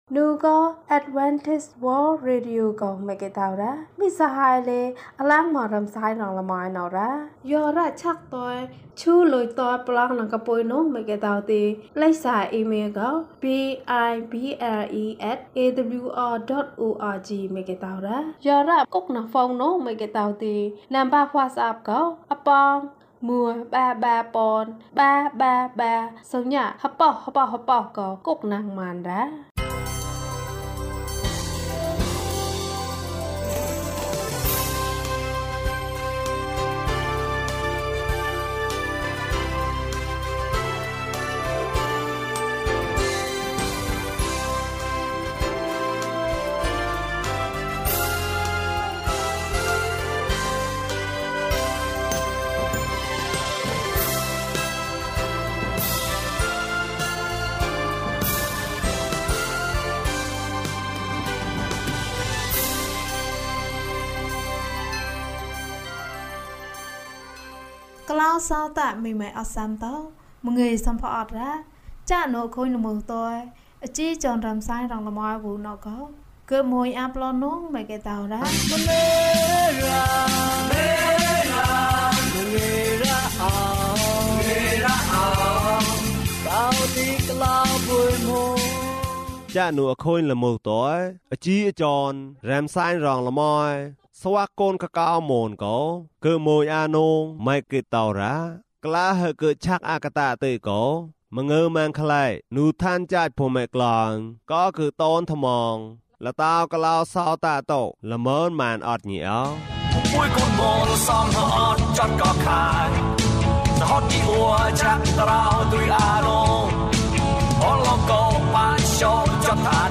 သမ္မာကျမ်းစာ။၀၂ ကျန်းမာခြင်းအကြောင်းအရာ။ ဓမ္မသီချင်း။ တရားဒေသနာ။